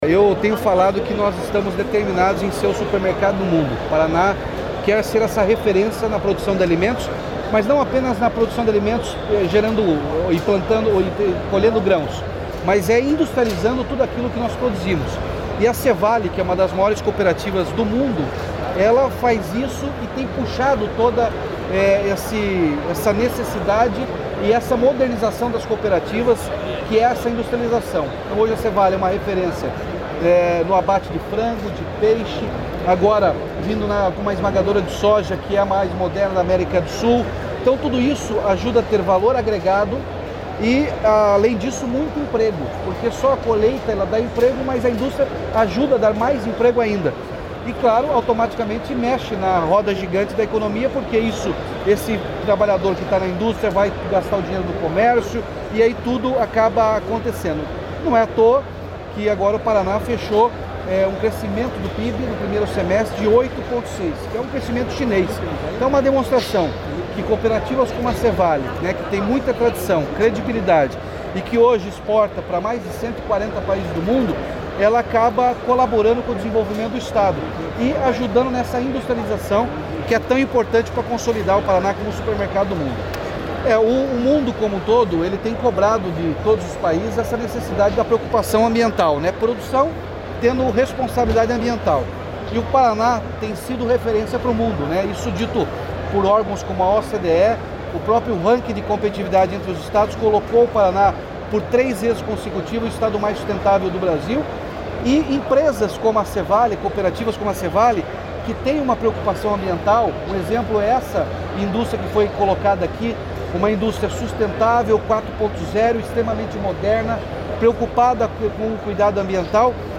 Sonora do governador Ratinho Junior sobre o anúncio de um convênio com a C.Vale para construção do Contorno de Palotina e a inauguração da esmagadora da cooperativa